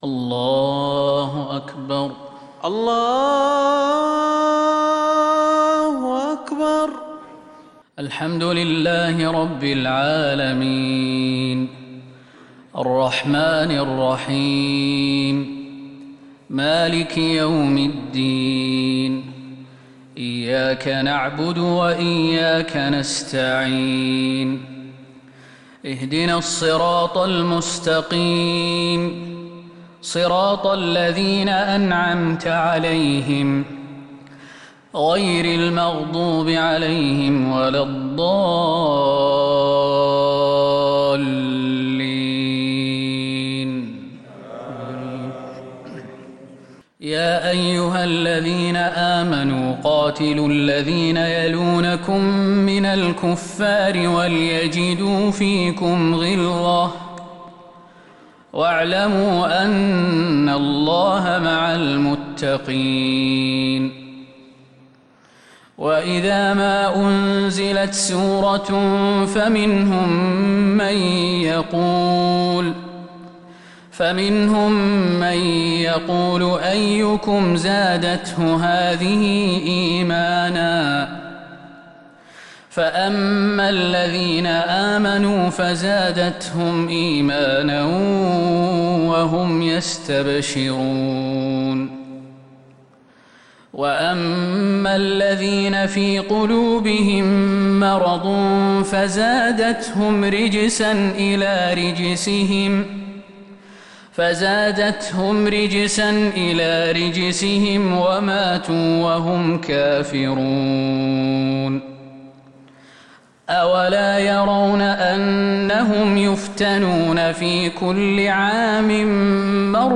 صلاة الفجر للقارئ خالد المهنا 6 ربيع الأول 1442 هـ
تِلَاوَات الْحَرَمَيْن .